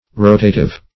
Rotative \Ro"ta*tive\, a. [Cf. F. rotatif.]
rotative.mp3